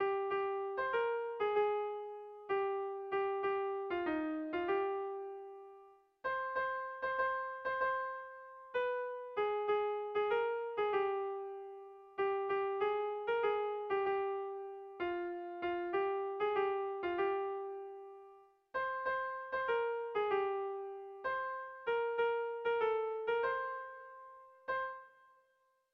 Sentimenduzkoa
Zortziko txikia (hg) / Lau puntuko txikia (ip)
ABDE